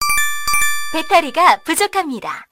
알림음 8_배터리가부족합니다.ogg